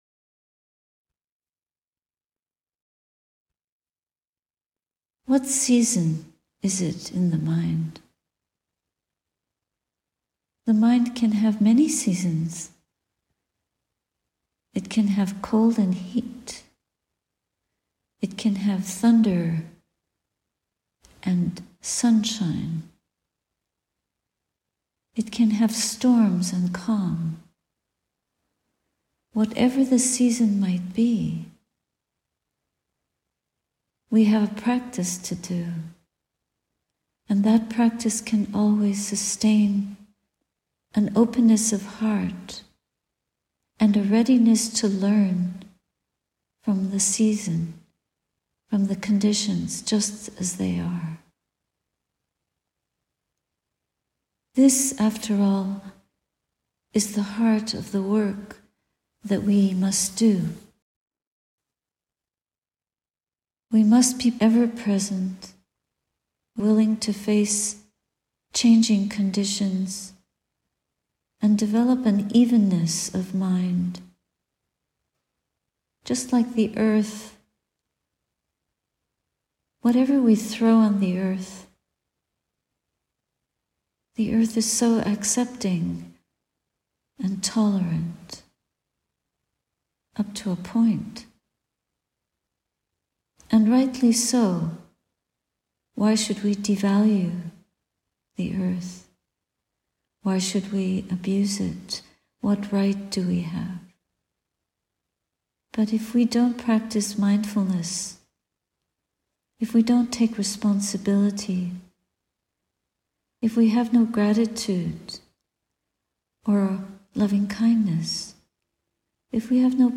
Brave Lotus Rising – Guided Meditation